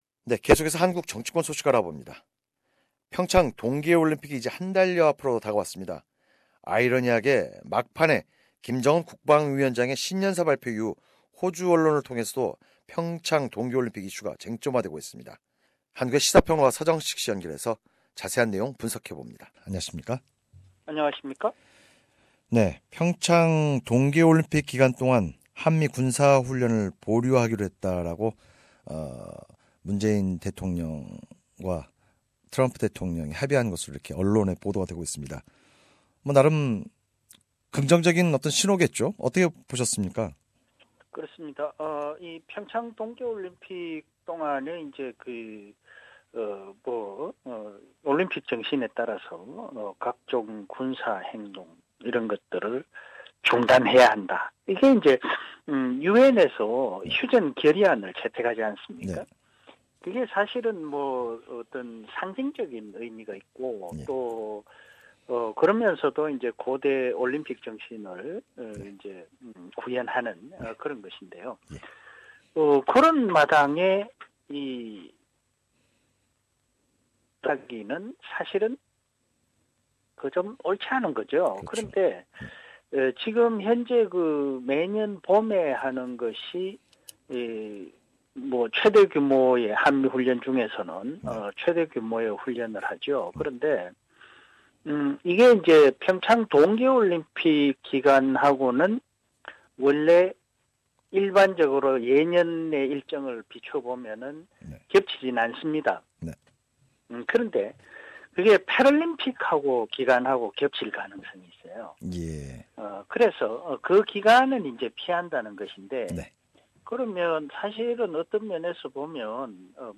More details from Korean political commentator